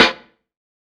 SNARE 034.wav